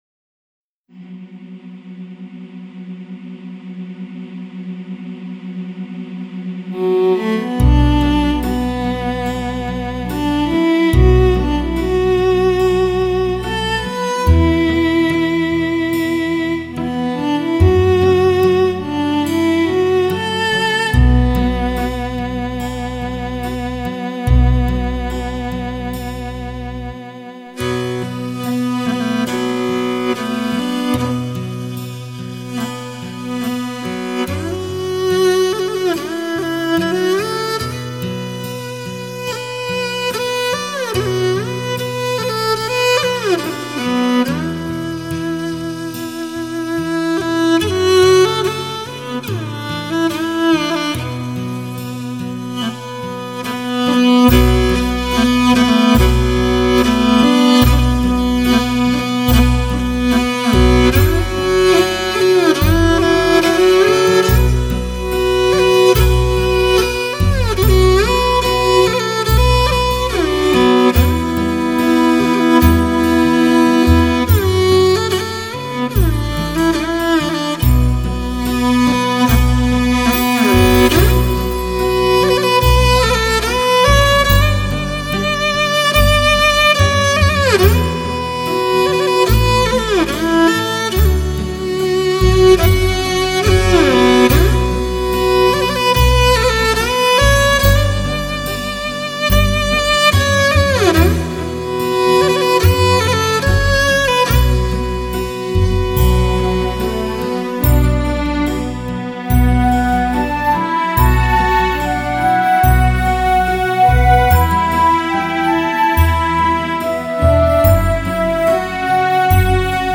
这些版本张张都是收藏精品，而且经过精心的后期处理，录音品质绝佳。